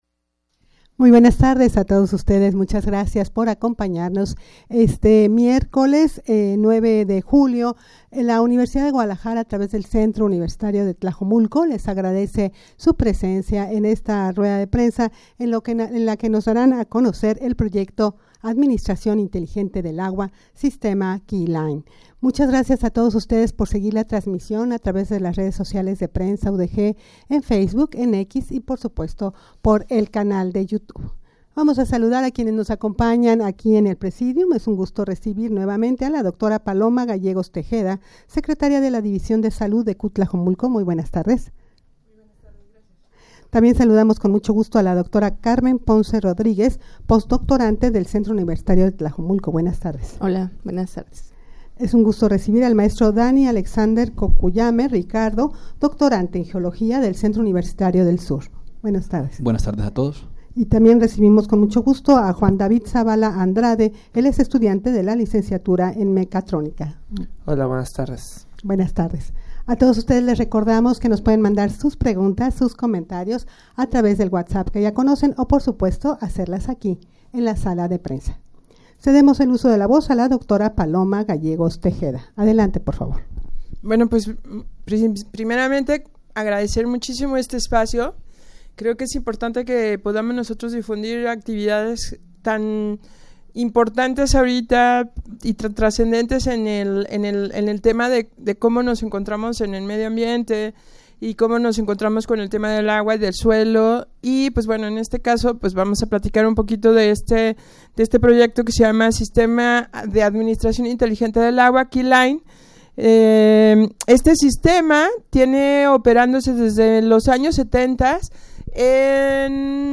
rueda-de-prensa-para-dar-a-conocer-el-proyecto-administracion-inteligente-del-agua-sistema-keyline.mp3